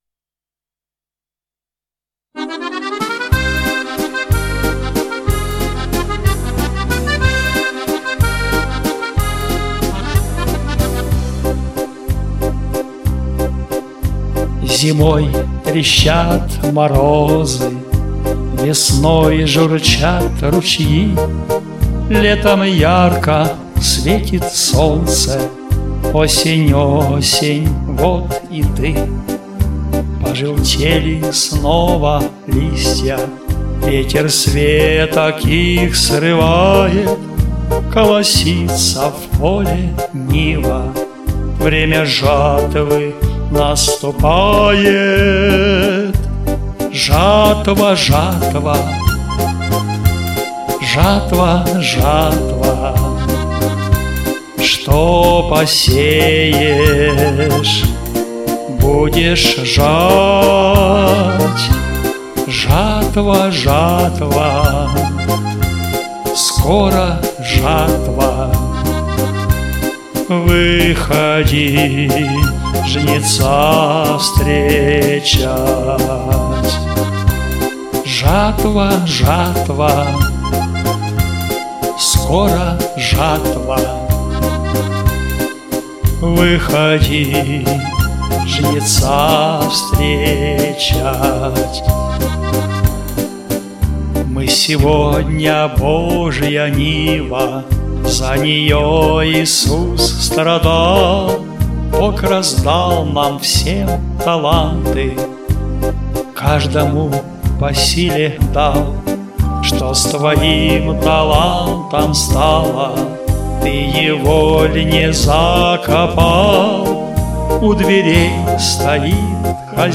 Теги: Христианские песни